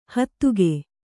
♪ hattuge